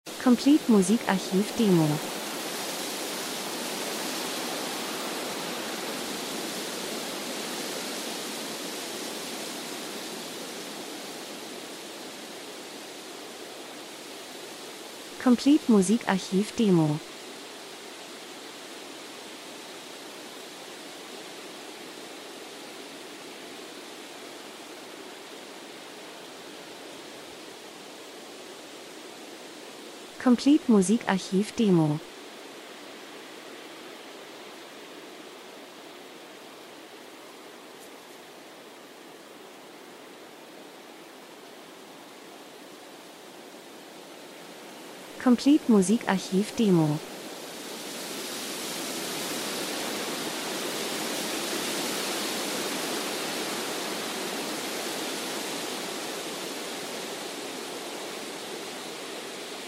Herbst -Geräusche Soundeffekt Wald, Rauschen, Wind 00:59